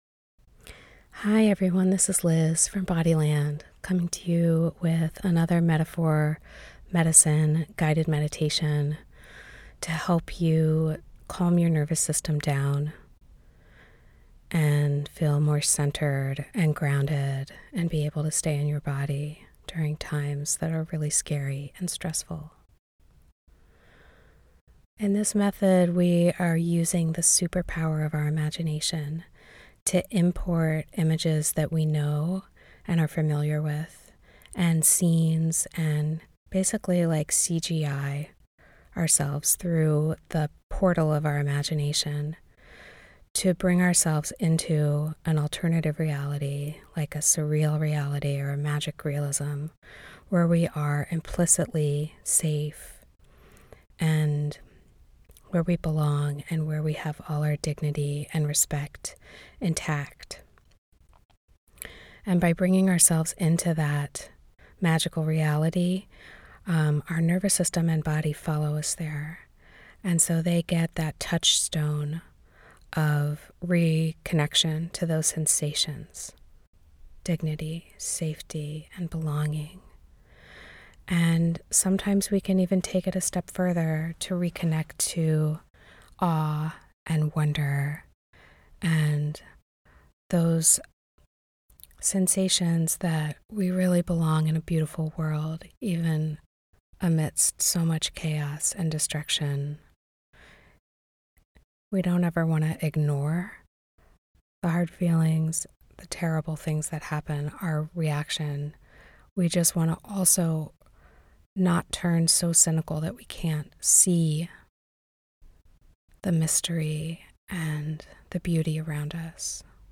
BODY LAND: Guided Meditations to Relax, Recharge, and Self-Regulate
Body Land is a collection of guided meditations that are accessible, experiential, and imaginative. These creative visualizations are informed by Eastern Medicine, Ecofeminism, Surrealist Art, and Somatic Therapies for the treatment of trauma. These creative visualizations help you feel more grounded, relaxed, and restored, and shift to a more positive frame of mind.